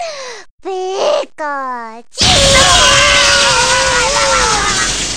Tiếng điện giật của Pikachu